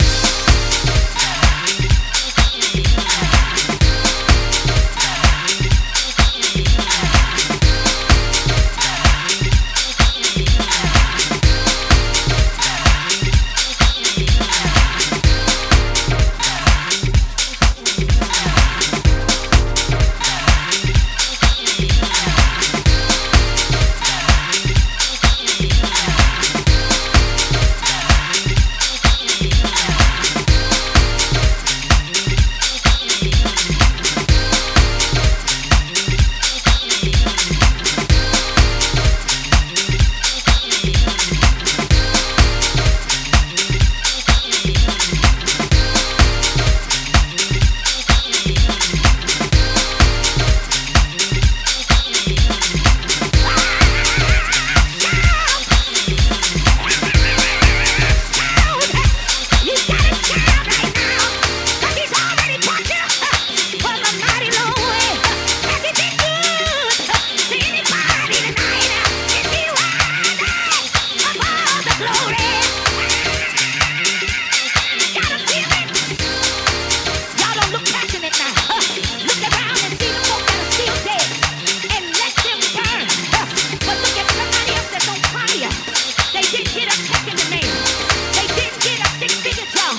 ジャンル(スタイル) DEEP HOUSE / HOUSE / DETROIT